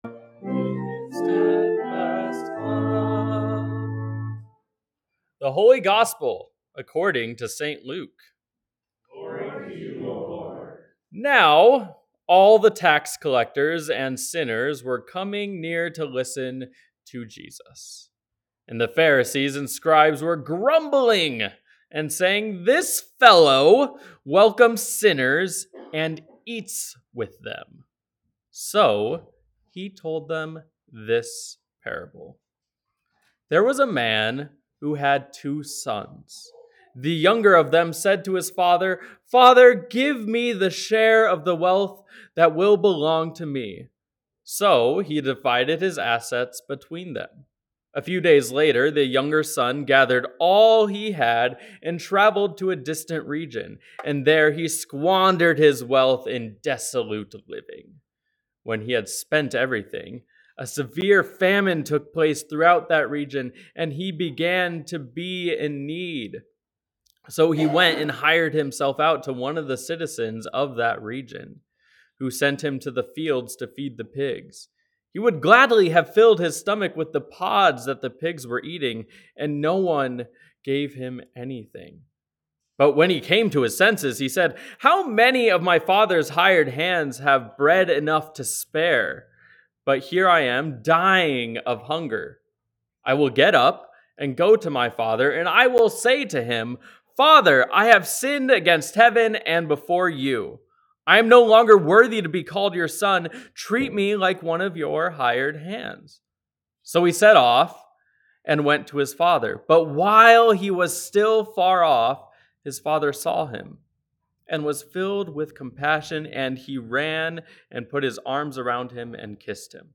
Sermons | Bethany Lutheran Church